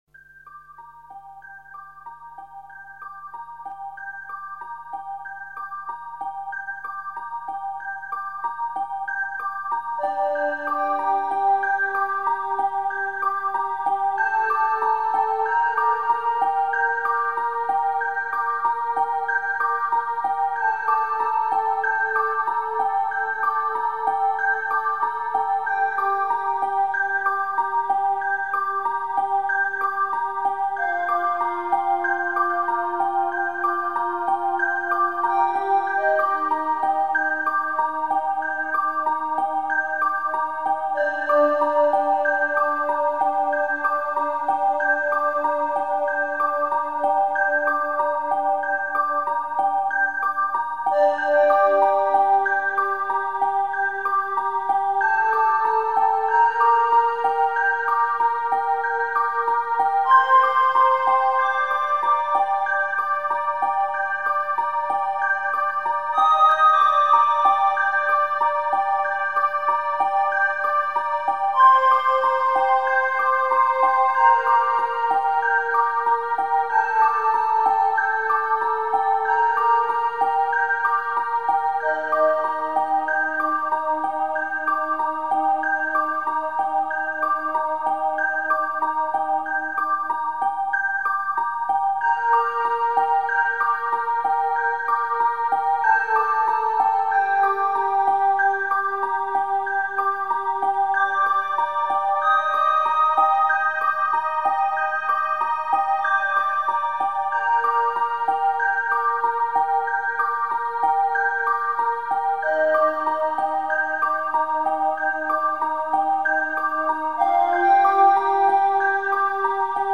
聲明，是莊嚴的人聲 梵唄。
四智梵語唱贊的經文聲、 散華海潮音般的唱誦聲、對揚問答式的祈贊、不動 王的贊誦，聽到沉默的那抹微笑。